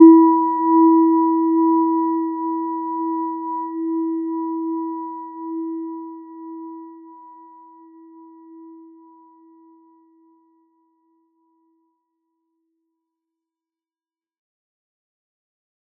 Gentle-Metallic-1-E4-mf.wav